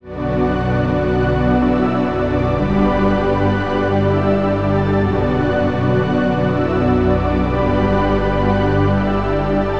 • Hot Sunset Pad synth - G.wav